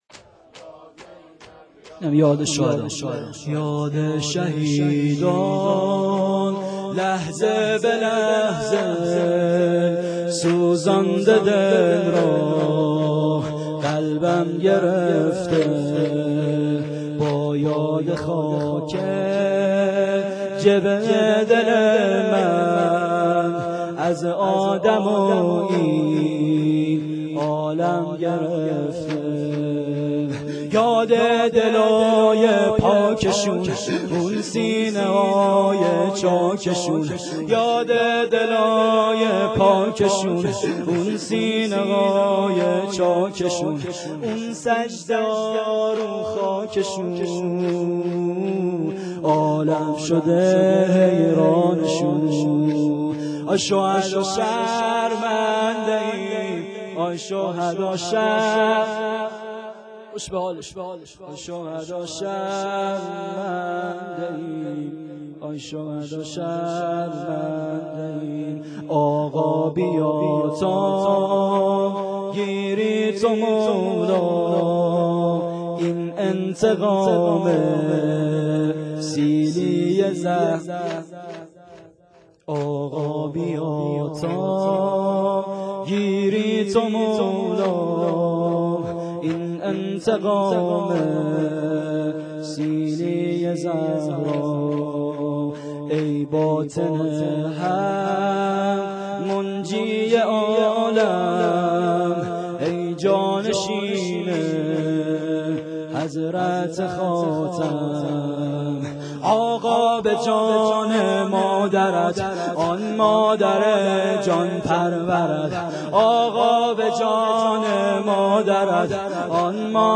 شور شهدایی